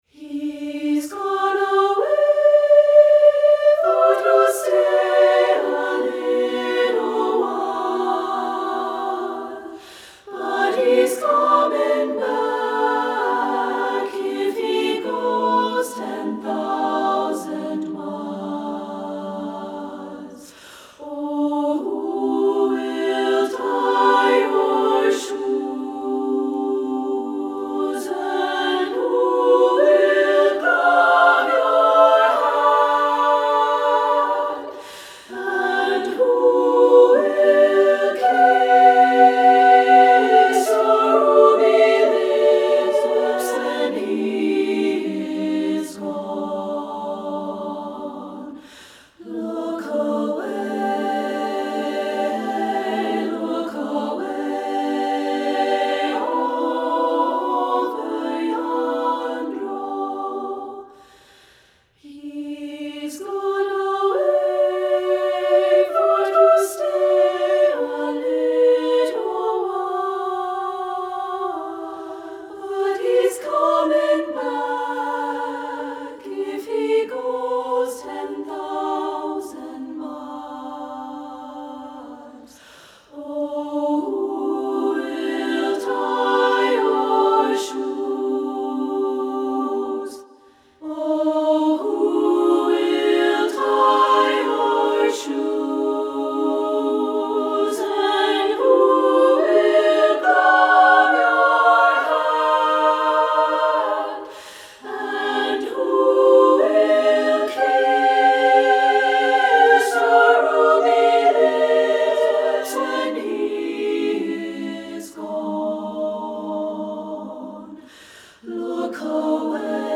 Voicing: SSAA,a cappella